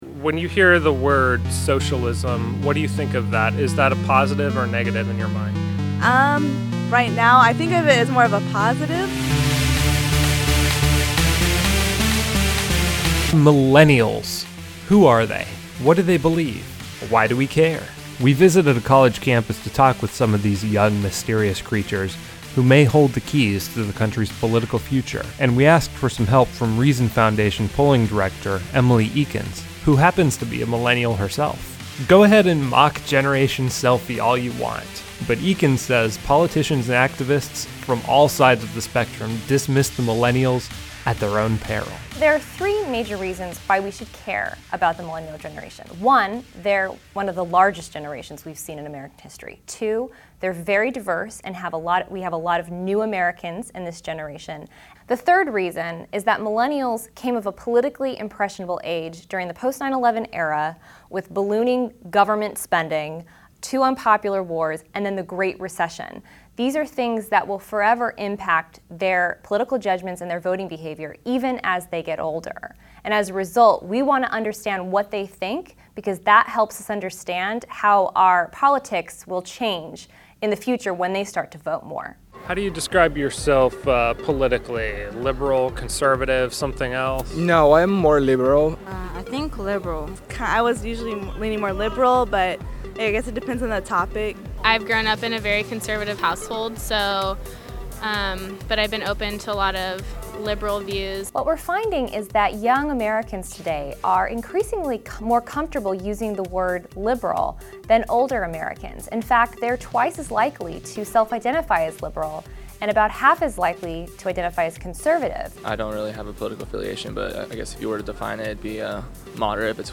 Armed with data from the recent Reason-Rupe poll on the same subject, Reason TV explored these questions on the campus of University of California, Irvine by asking students in the 18-29 age group to talk about their political philosophies, their attitudes towards Democrats and Republicans, their reactions to the word socialism, and their perspectives on entrepreneurship.